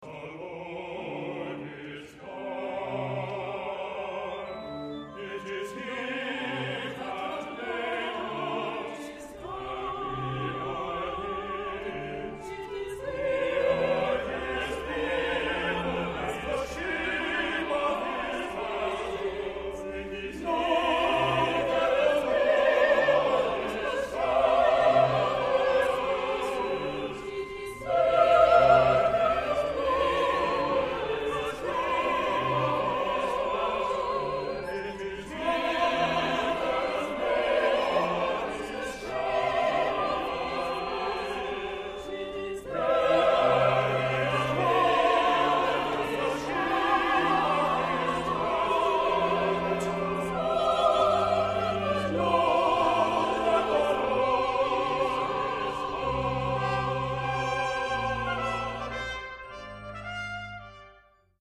Music Category:      Christian